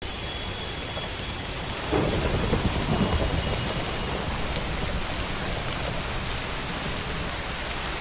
thundstorm_animation_audio.wav